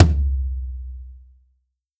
Tom 15.wav